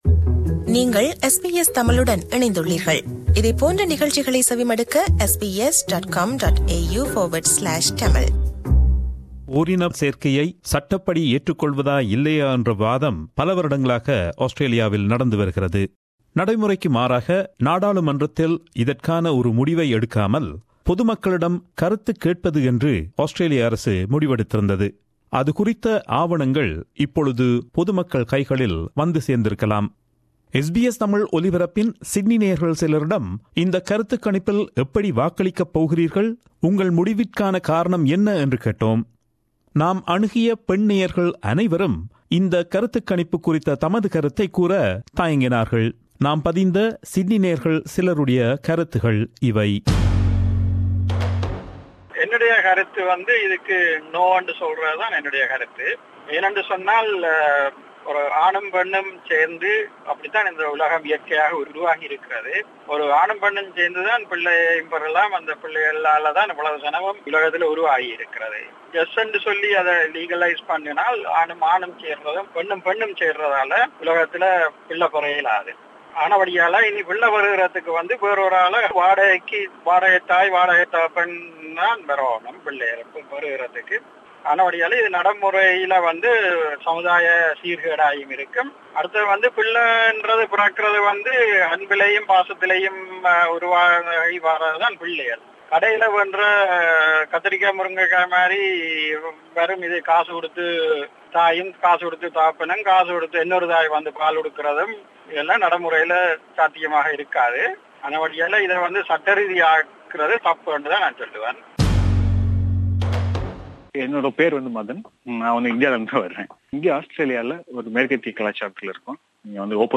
VoxPop on Same-Sex Marriage